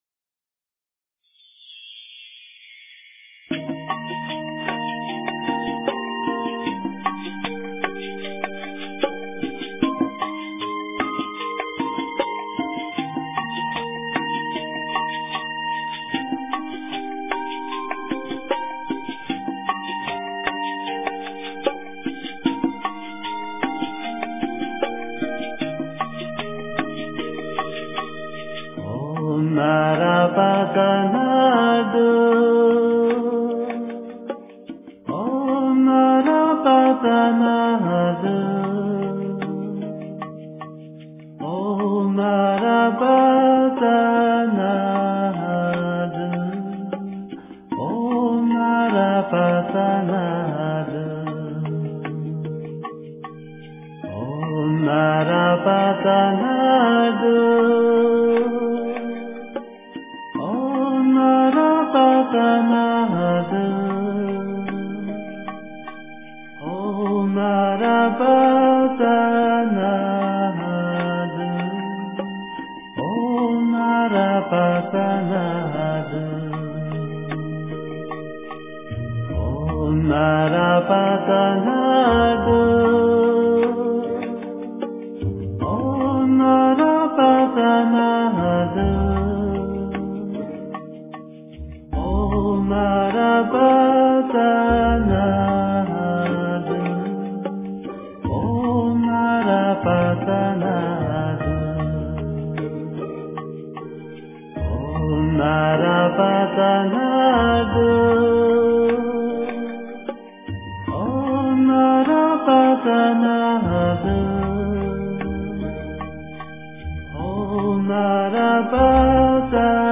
诵经
佛音 诵经 佛教音乐 返回列表 上一篇： 般若波罗密多心经 下一篇： 往生净土神咒 相关文章 达摩禅悟--古筝 达摩禅悟--古筝...